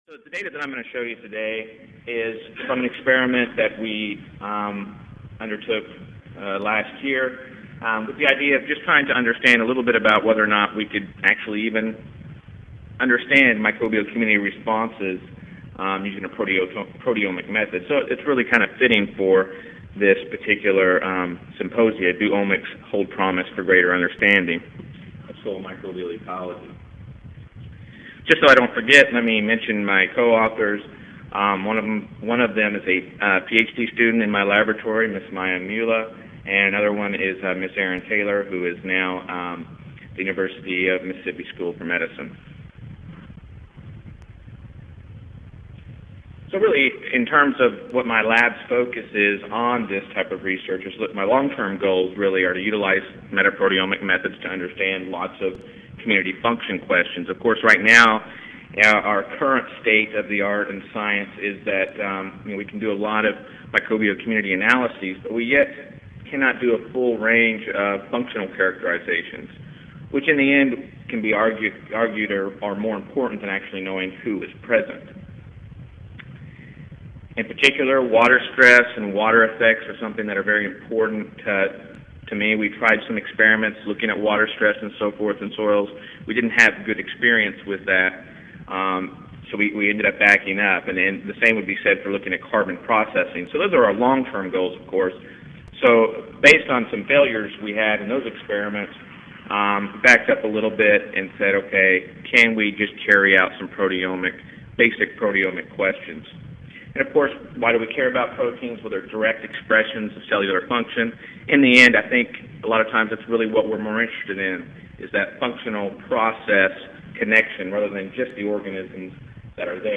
Mississippi State University Audio File Recorded presentation